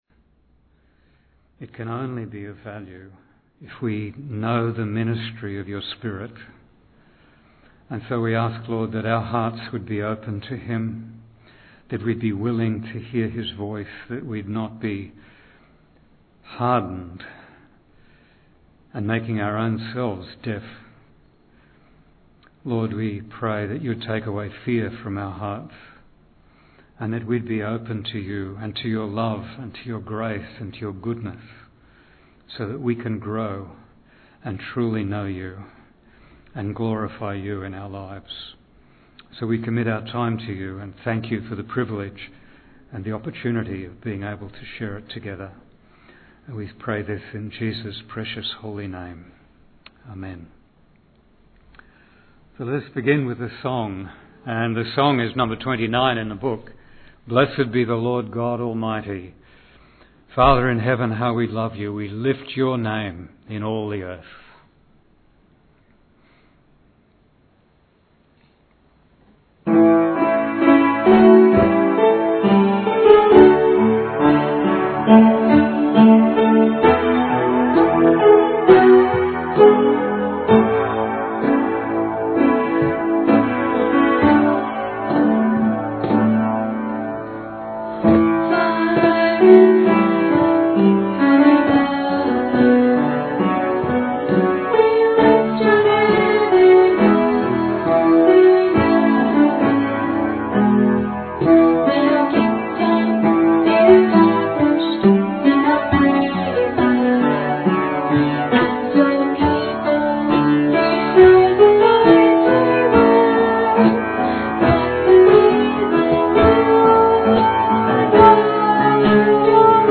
19 July 2020 Sunday Meetings 主日聚会